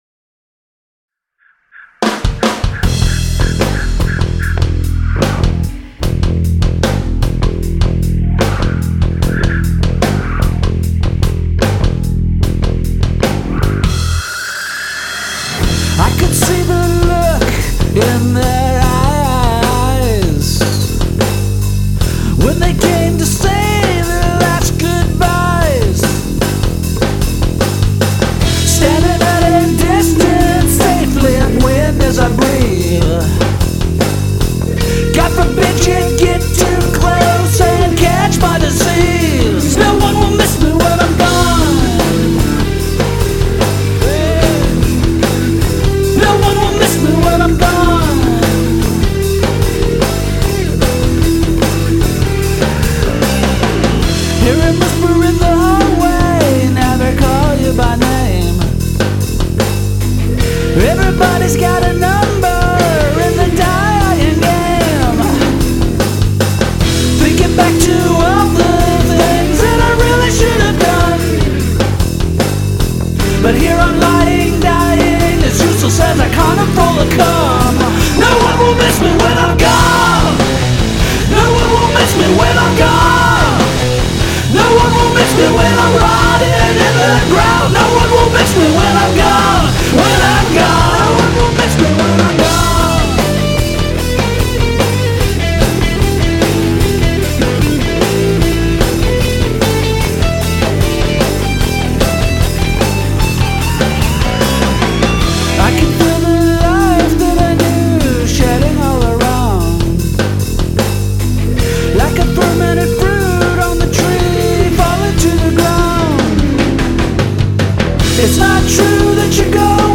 Significant use of cacophony.